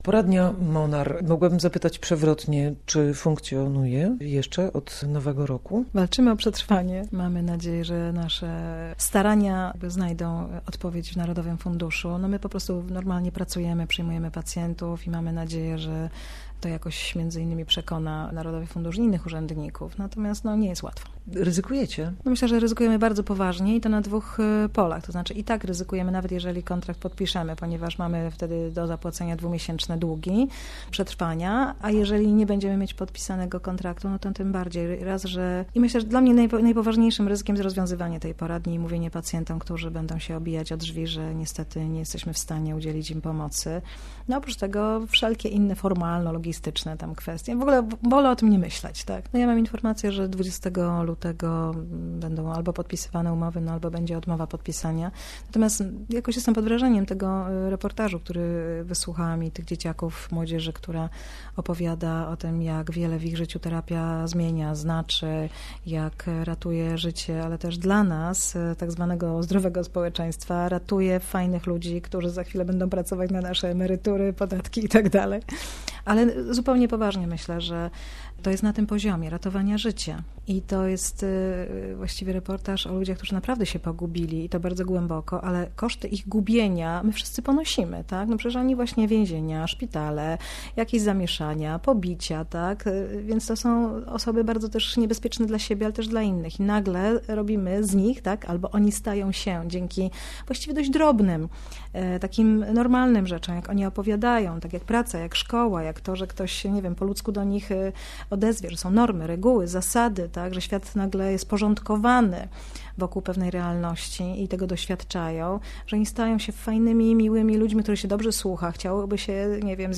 Cuda w Wierzenicy - reportaż - Radio Poznań